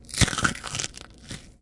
紧缩2
描述：各种用手捏碎的饼干。 用Zoom H2.
Tag: 裂化 紧缩 粉碎